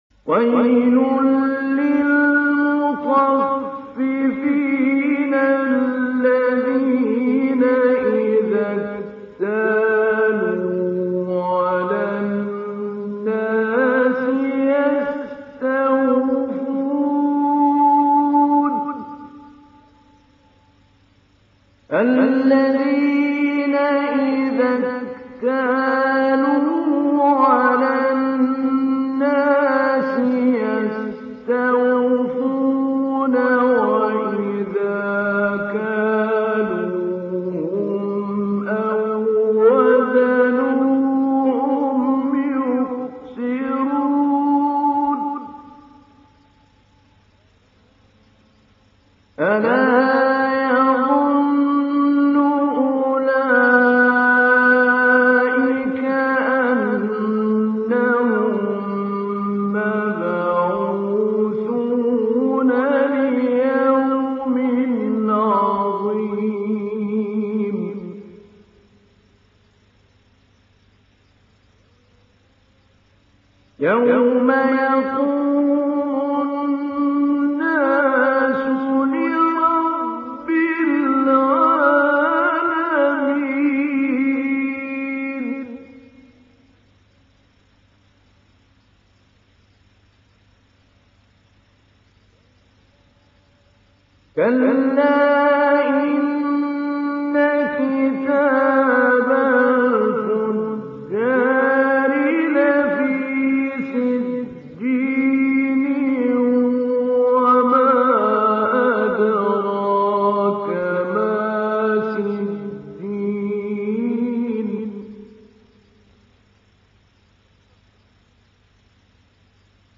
সূরা আল-মুতাফফিফীন mp3 ডাউনলোড Mahmoud Ali Albanna Mujawwad (উপন্যাস Hafs)